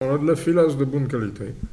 Xanton-Chassenon
Catégorie Locution